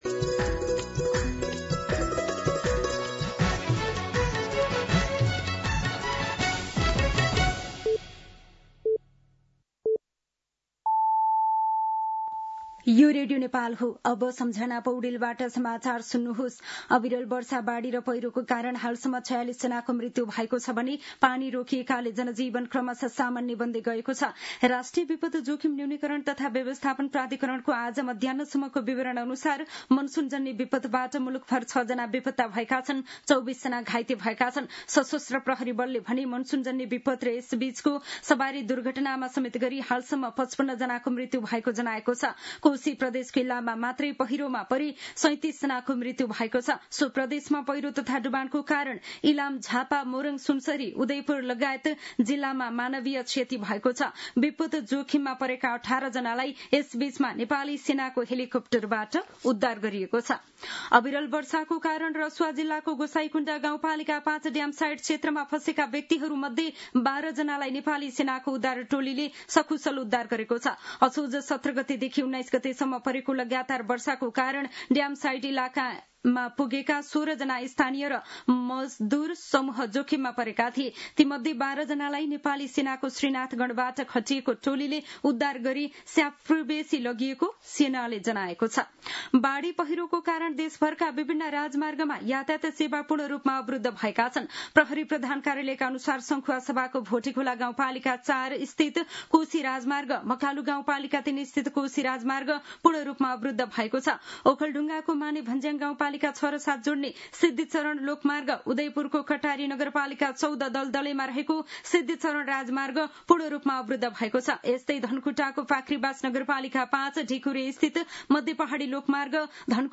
दिउँसो ४ बजेको नेपाली समाचार : २० असोज , २०८२
4-pm-Nepali-News-2.mp3